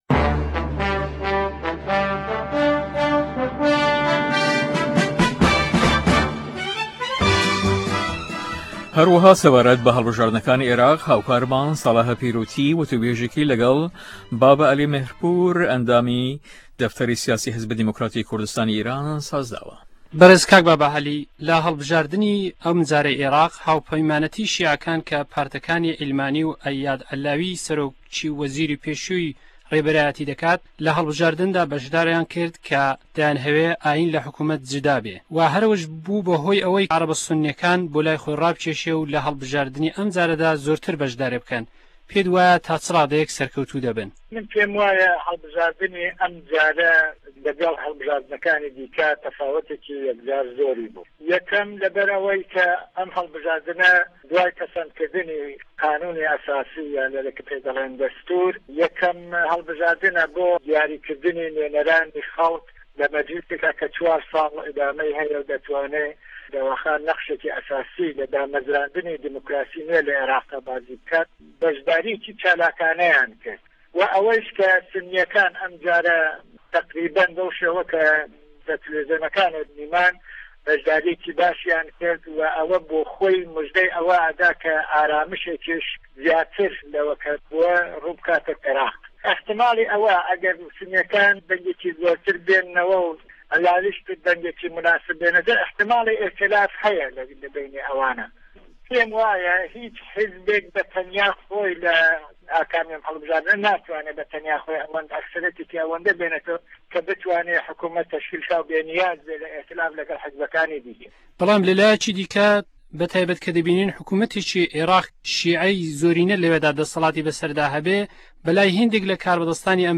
هه‌ڤپه‌یڤینا